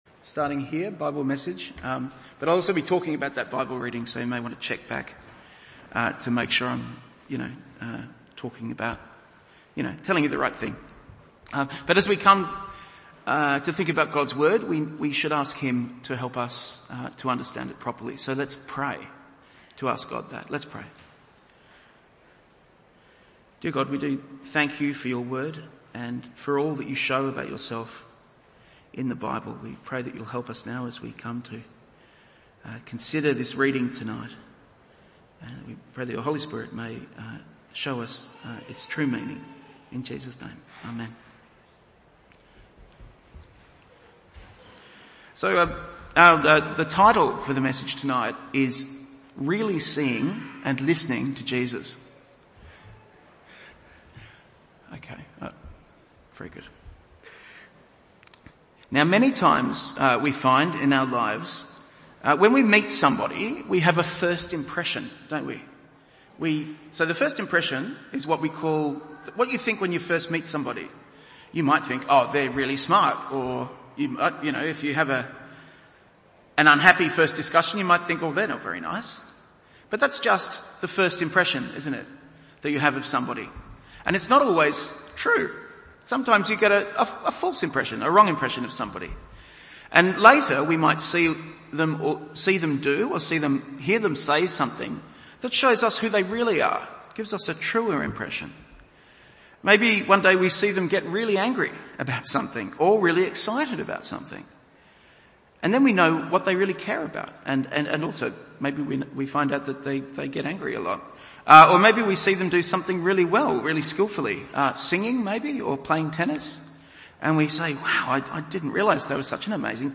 Evening Service Meeting Jesus: Really seeing and listening to Jesus Luke 9:28-36 1. Seeing Jesus’ Glory 2. This in my Son and I have chosen him 3.